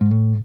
SLIDESOLO1.wav